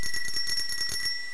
Bells
Bells.wav